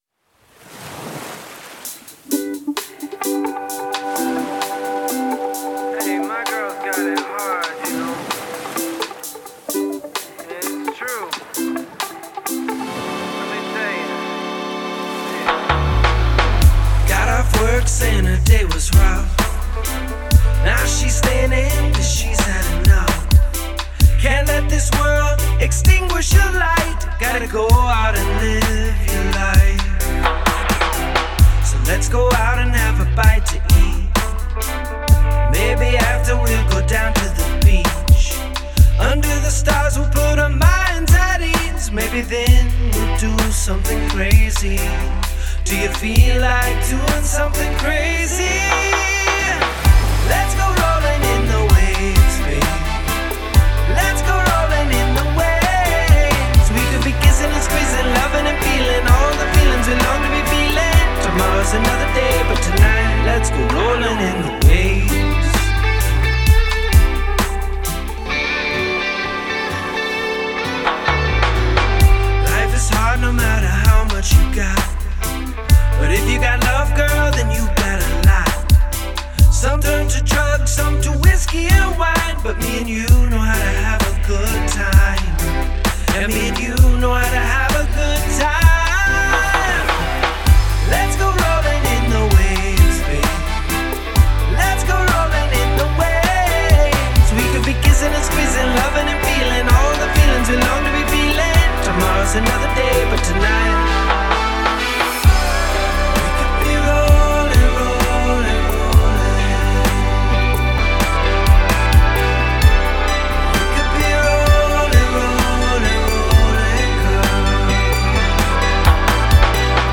Genre: Reggae.